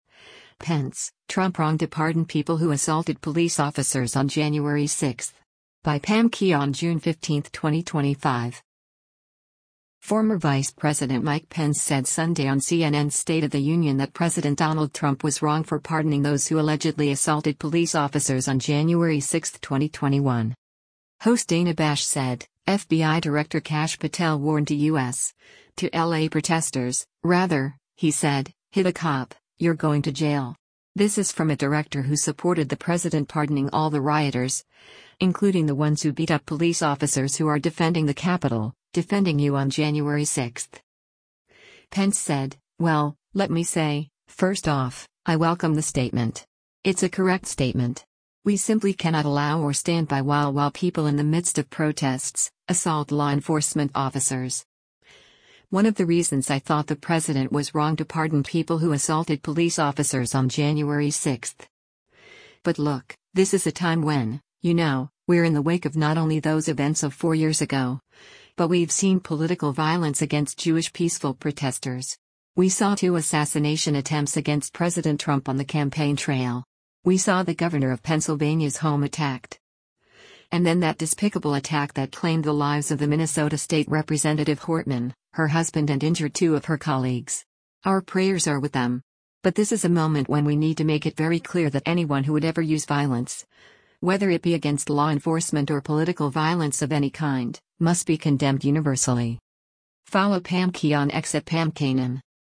Former Vice President Mike Pence said Sunday on CNN’s “State of the Union” that President Donald Trump was “wrong” for pardoning those who allegedly assaulted police officers on January 6, 2021.